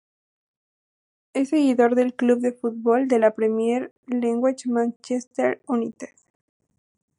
se‧gui‧dor
/seɡiˈdoɾ/